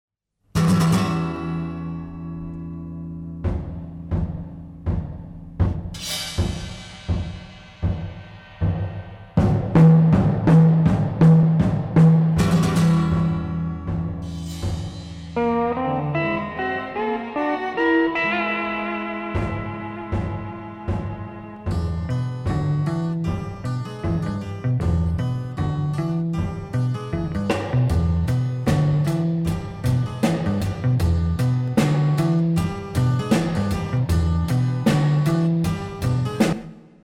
highly original, exotic, rhythmic score